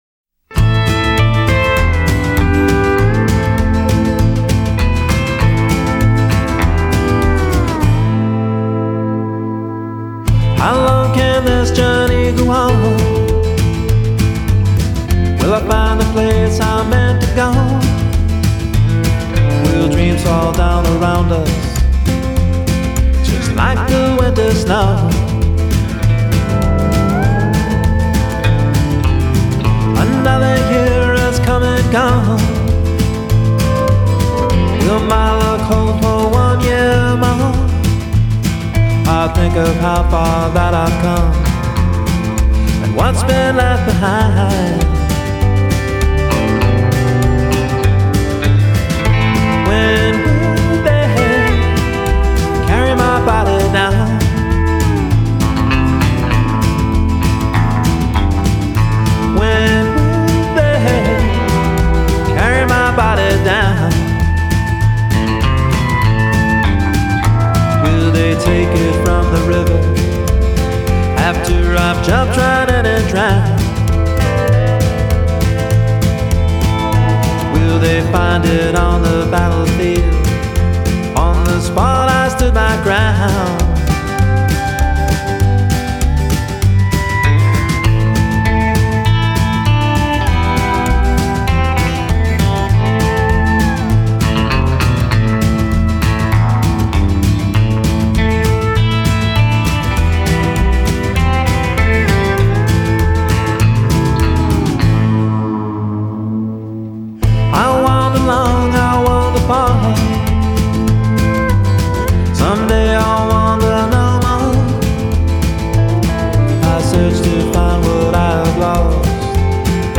hillbilly honky-tonk
blending rockabilly and rock 'n' roll